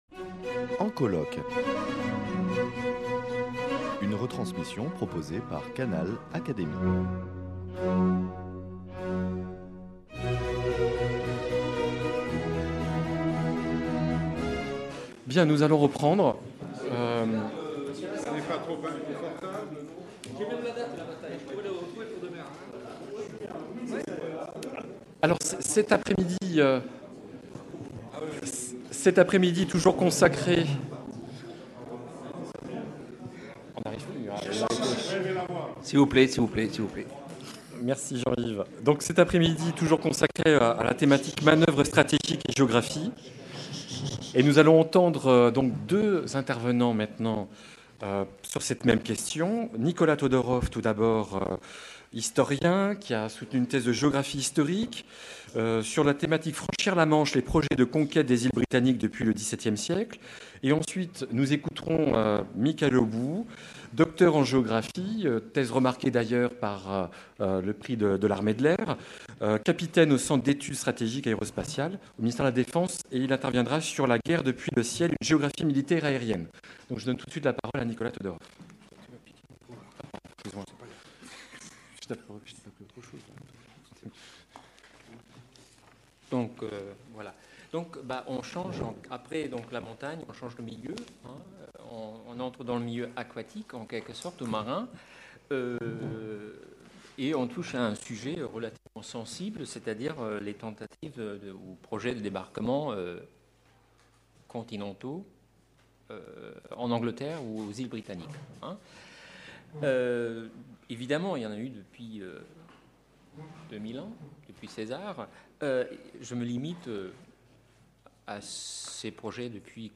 Retransmission des journées d’études « Guerre et géographie » - Partie 5